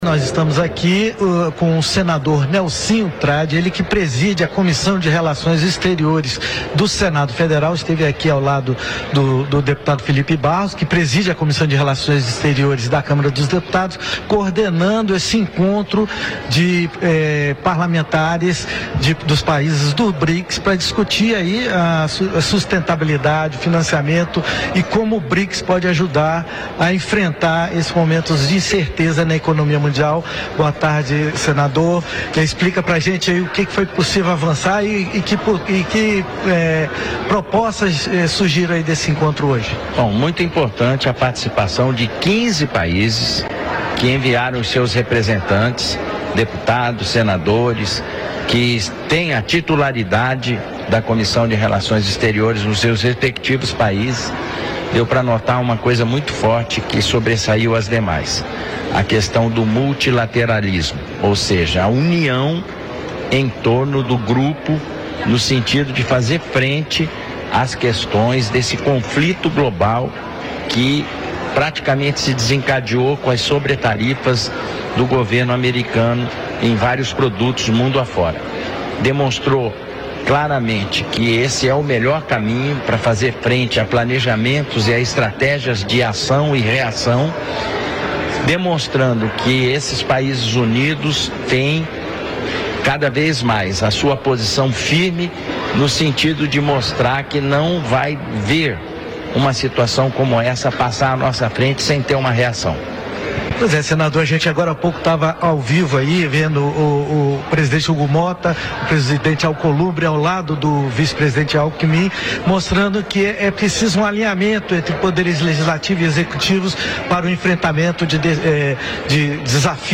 Depois da reunião de presidentes das Comissões de Relações Exteriores dos países integrantes do bloco, nesta terça-feira (3), o senador Nelsinho Trad (PSD-MS), que preside a CRE no Senado, avaliou, em entrevista à TV Câmara, que os parlamentares mostraram-se empenhados em fortalecer o Brics e investir no multilateralismo. A ideia é reagir às recentes medidas adotadas pelo governo americano, que apontam para uma guerra comercial e para o fechamento dos Estados Unidos para o comércio internacional.